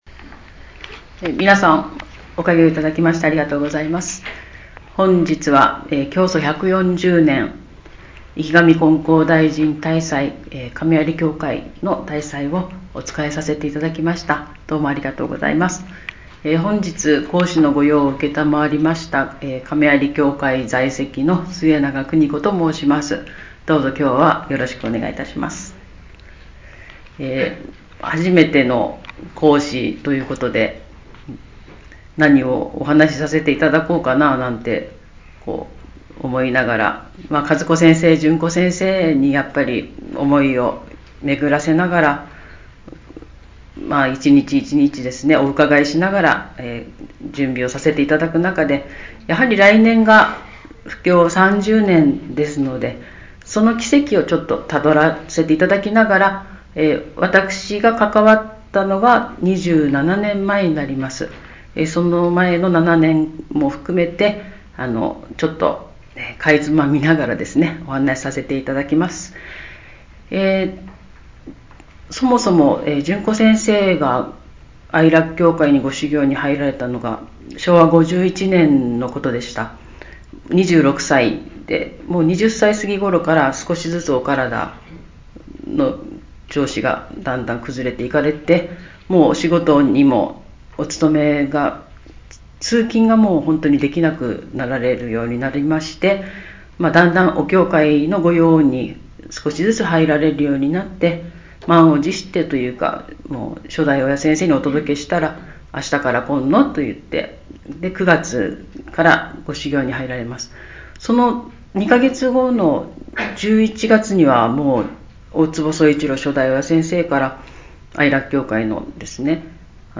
教祖140年生神金光大神大祭･講話